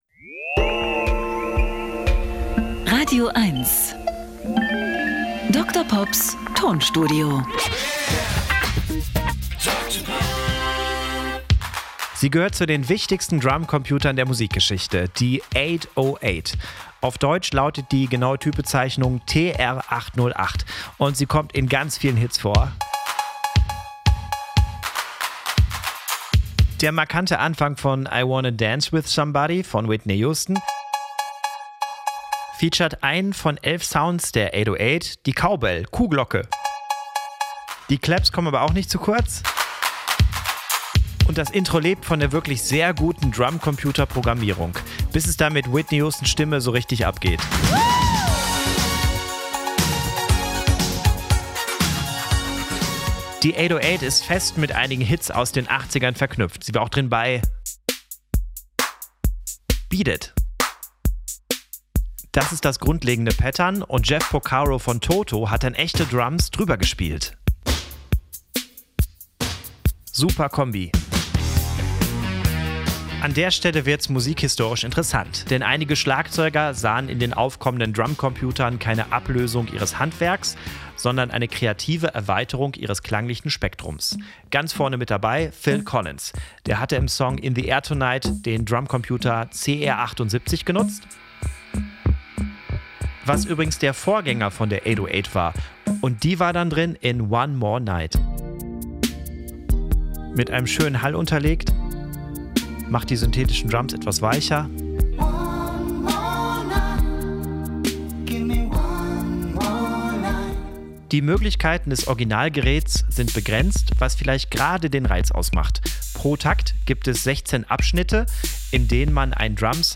Er therapiert mit Musiksamples und kuriosen, aber völlig wahren Musikfakten.
Comedy